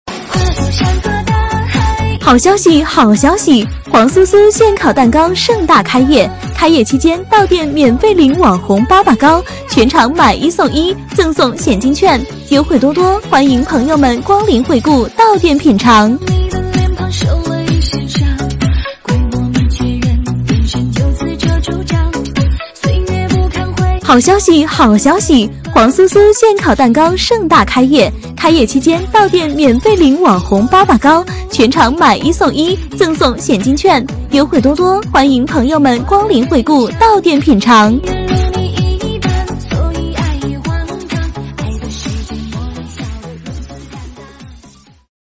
【女31号促销】煌酥酥现烤糕点.mp3